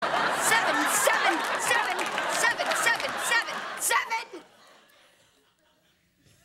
Tags: seinfeld airhorn